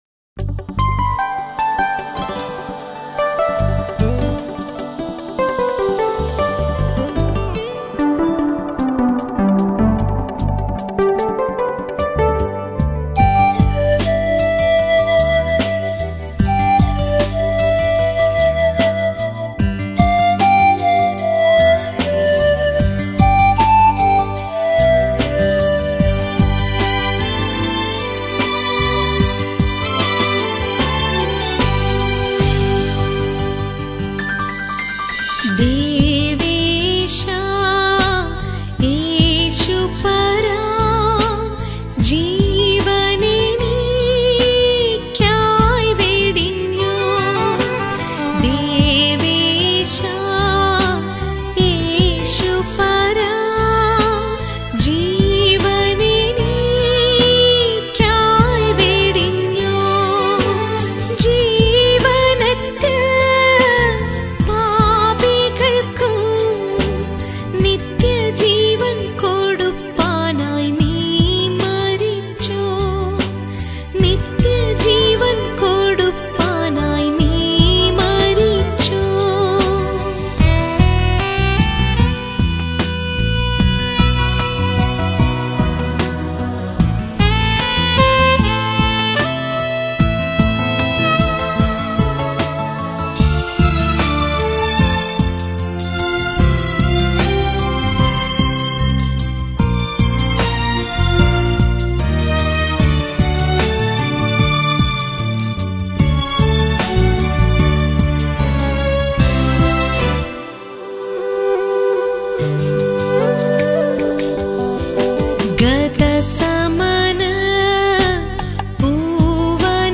Devotional Songs - Devesha Yesupara